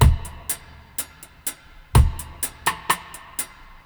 62-FX-02.wav